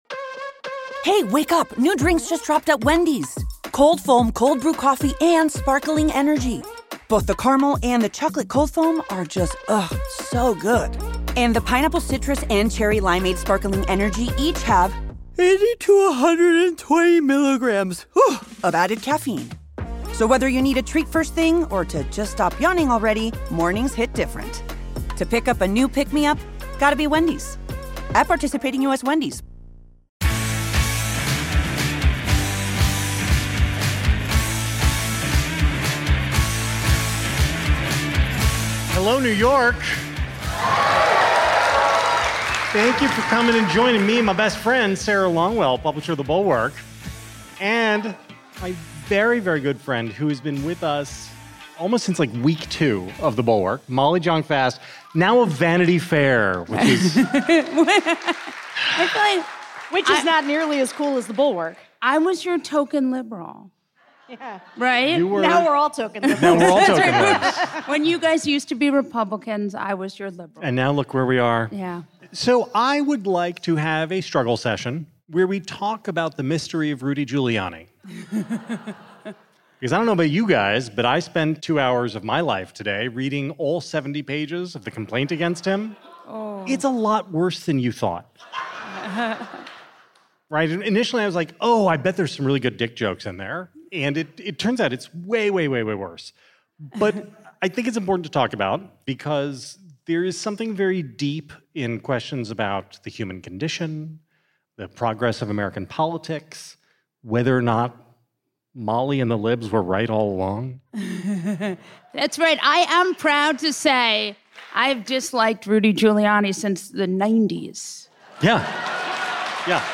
Anxiety vs Reality (LIVE from NYC with Molly Jong-Fast)
Politics, News, News Commentary